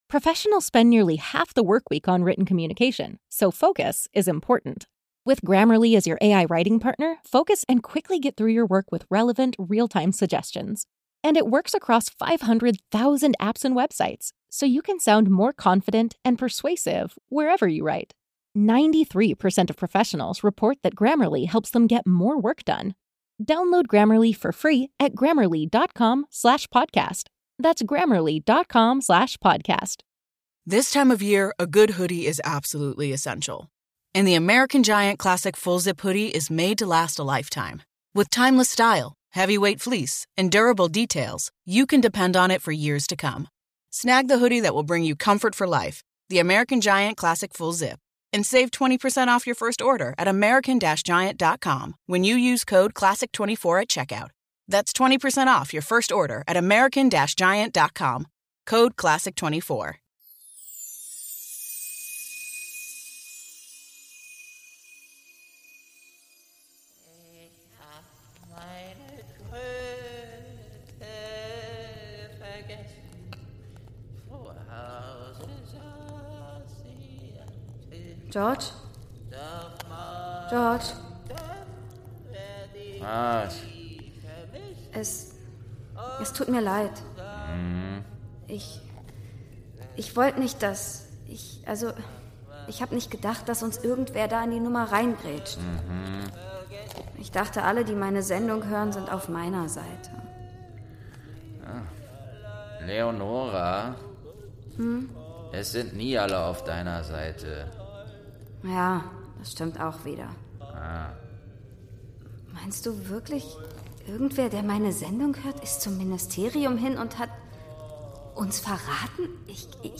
17. Türchen | Zelle im Ministerium - Eberkopf Adventskalender ~ Geschichten aus dem Eberkopf - Ein Harry Potter Hörspiel-Podcast Podcast